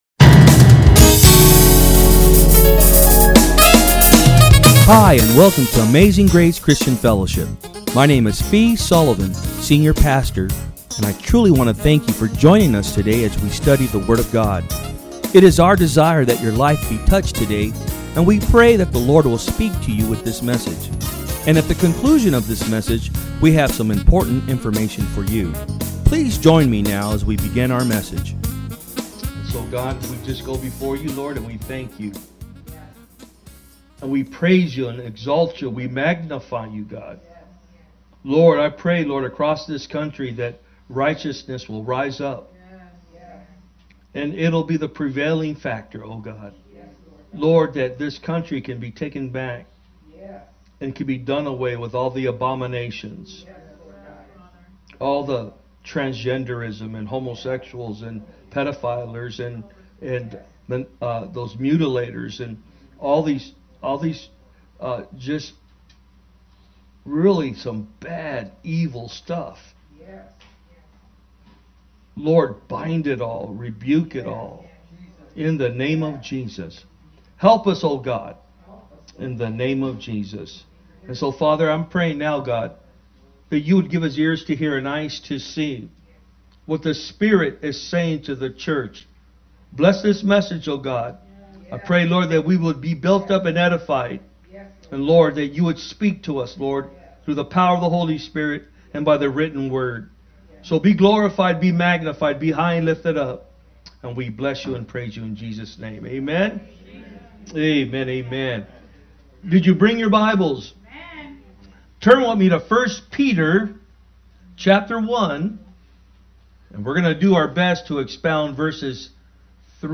From Service: "Sunday Am"